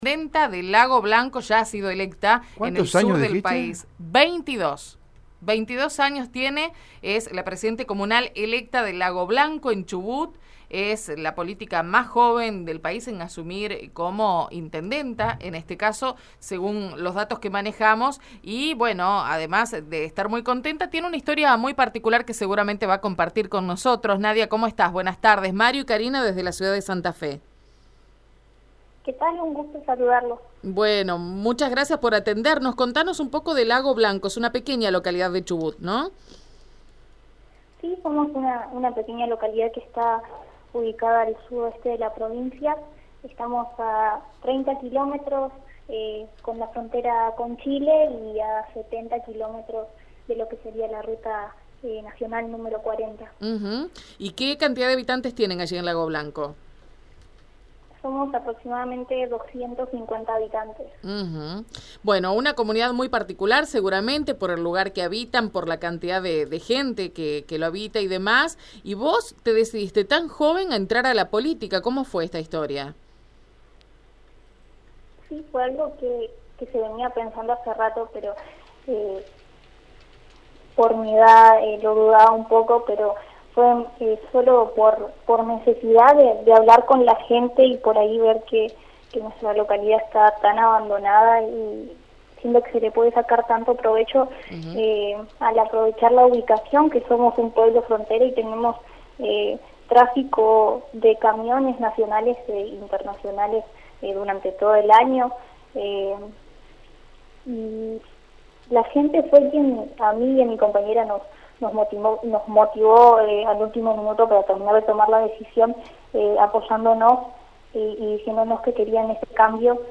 La Intendenta más joven del país tiene 22 años y habló en Radio EME | Radio EME